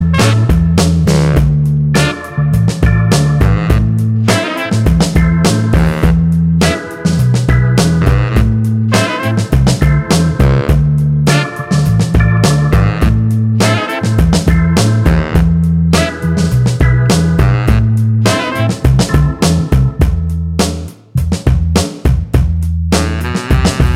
No Guitars Pop (2000s) 4:12 Buy £1.50